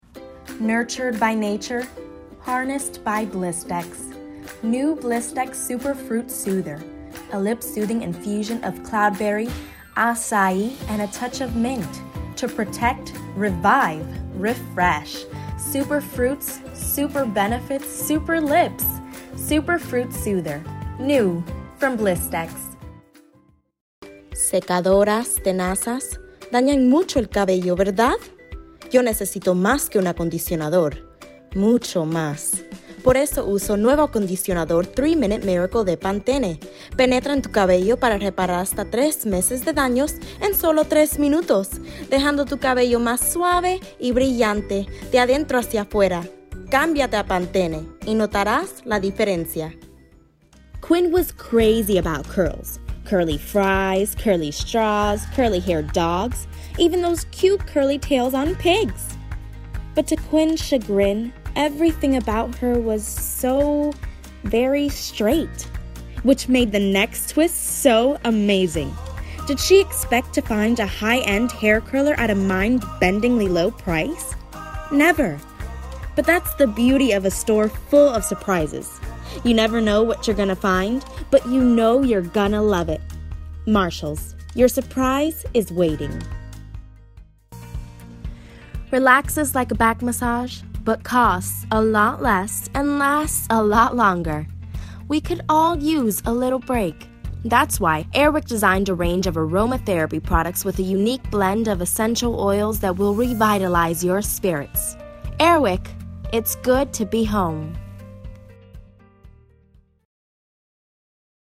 Voiceovers